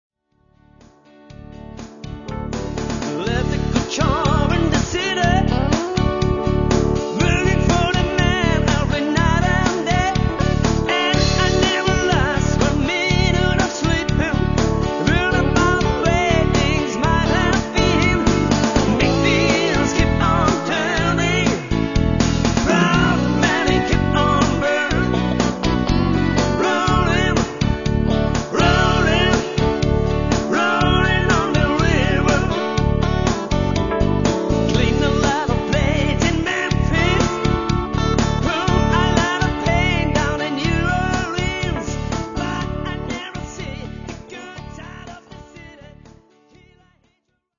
Audio-Demos 22 kHz Mono